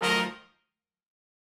GS_HornStab-F7b2sus4.wav